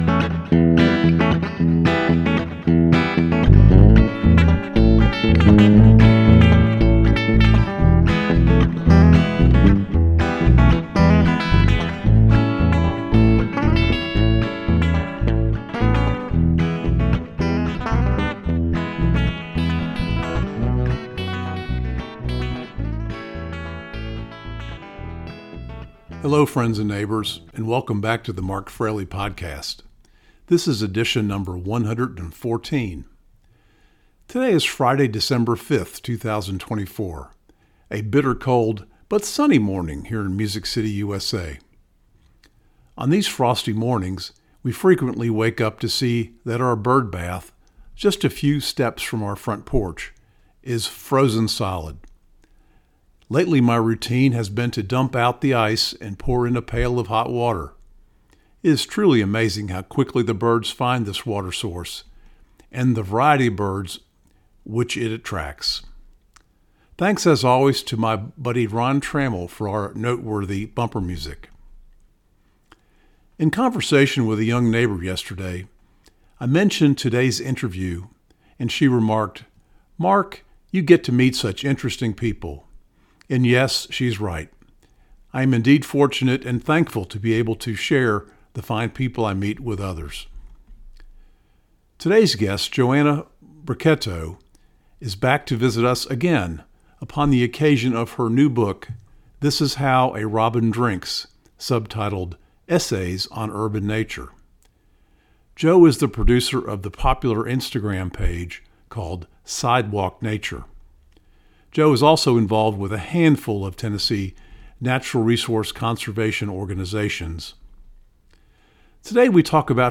Embedded within the interview is a special message/lesson for the managers of city parks everywhere.